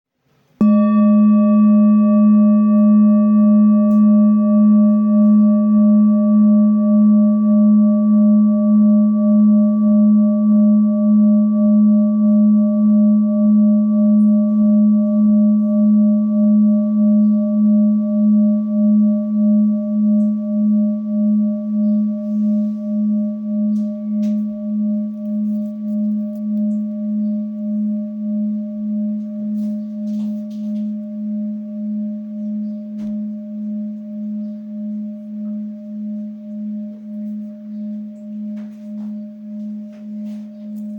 Full Moon Bowl, Buddhist Hand Beaten, Moon Carved, Antique Finishing, Select Accessories
Material Seven Bronze Metal
This is a Himalayas handmade full moon singing bowl. The full moon bowl is used in meditation for healing and relaxation sound therapy.